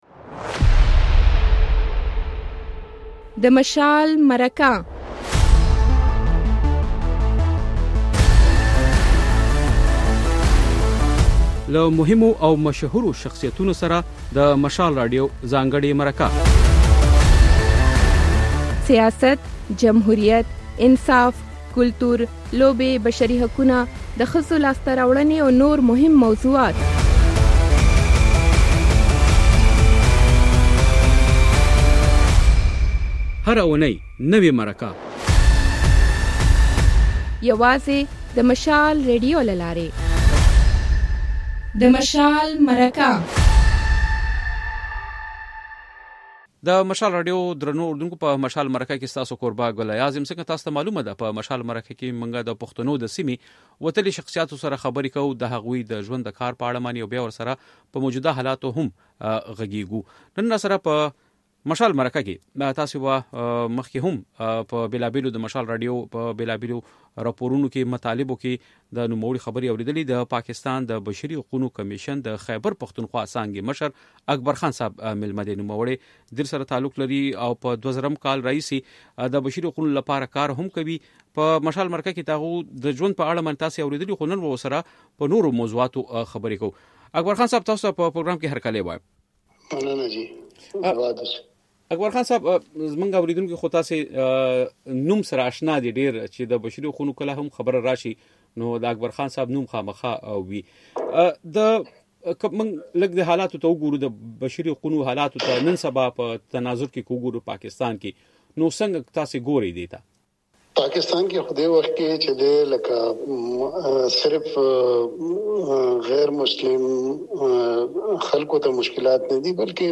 په مرکه کې مو له نوموړي سره په پاکستان کې د لږکیو لپاره د ملي کمېشن جوړولو لپاره د ډېسېمبر په لومړۍ اونۍ کې د منظور شوې قانوني مسودې پر ارزښت بحث کړی دی. بشپړه مرکه واورئ.